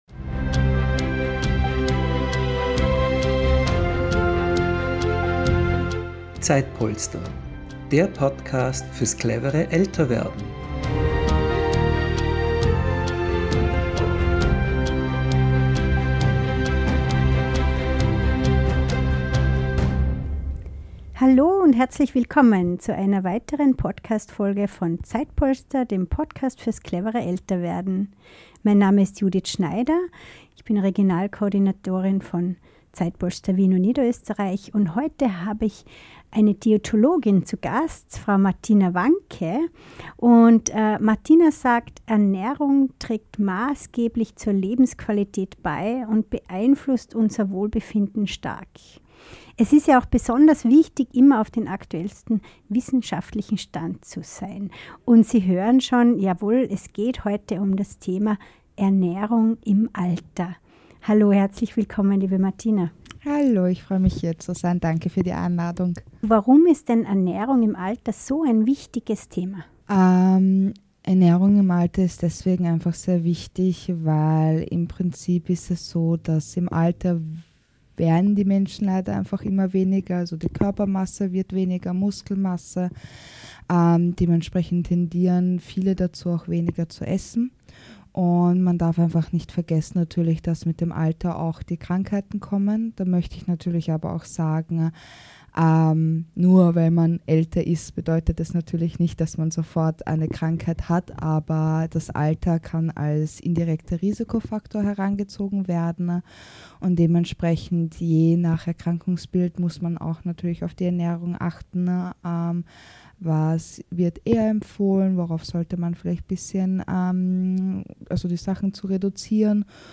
Diesen und weiteren Fragen gehen wir in diesem Gespräch auf den Grund.